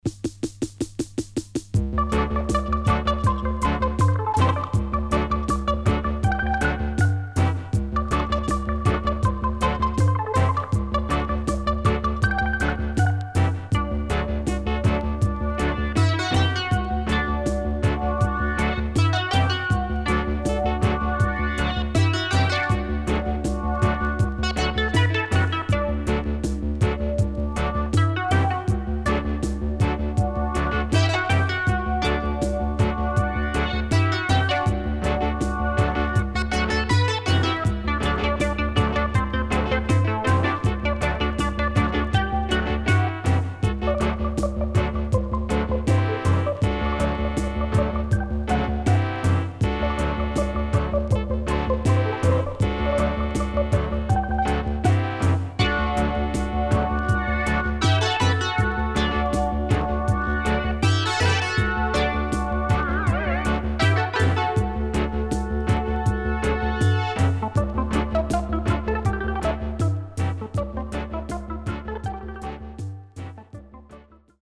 HOME > REISSUE USED [DANCEHALL]
riddim
SIDE A:少しチリノイズ入りますが良好です。